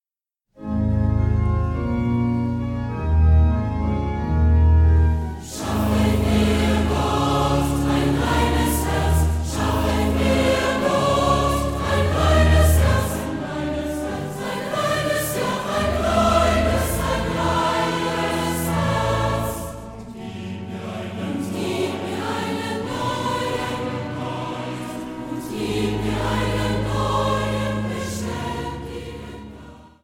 • kurzweilige Zusammenstellung verschiedener Live-Aufnahmen
Chor, Klavier